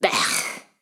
Gesto de asco de una mujer
exclamación
interjección
Sonidos: Acciones humanas
Sonidos: Voz humana